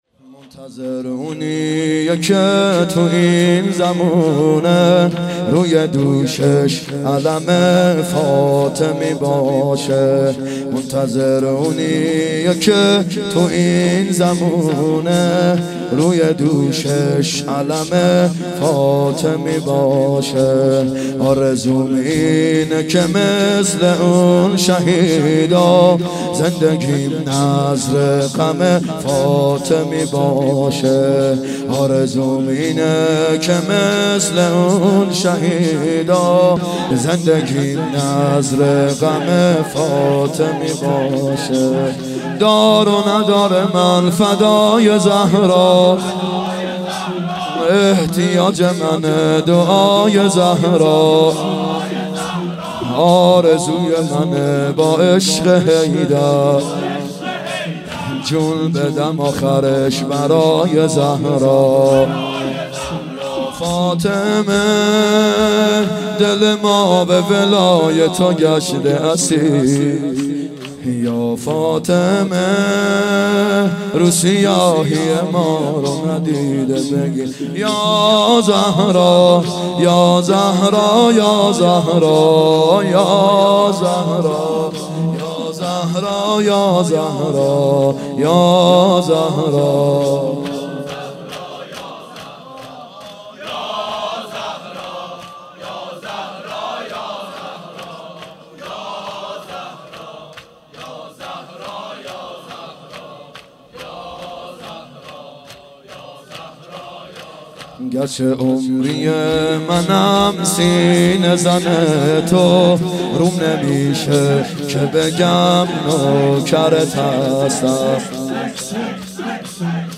فاطمیه دوم هیئت یامهدی (عج)